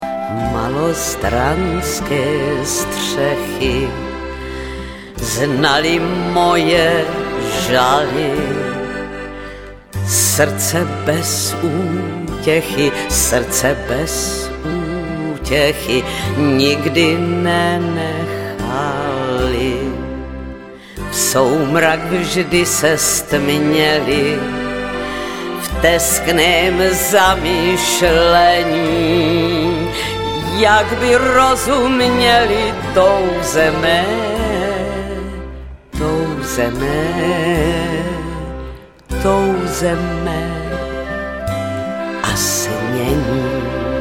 Studio A České televize (smyčce)